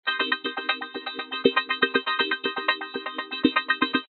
同步线 "电子低音循环